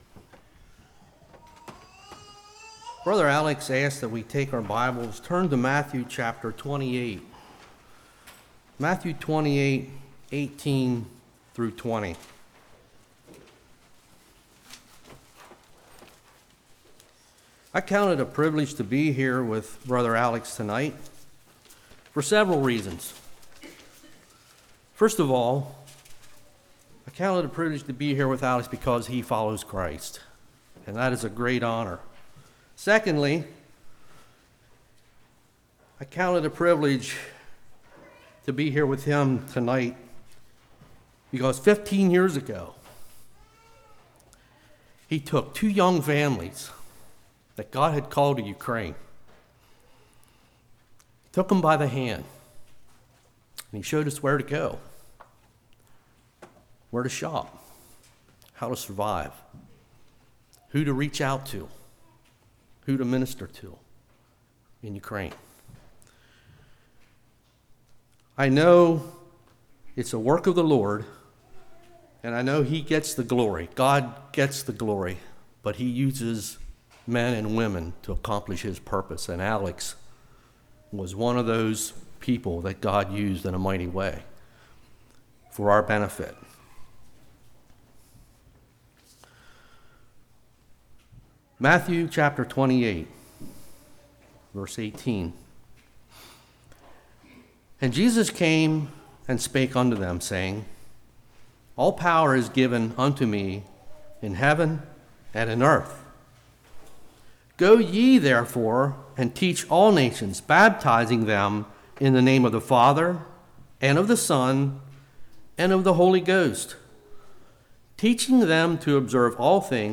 Matthew 28:18-20 Service Type: Evening The command to missions Do missions still matter?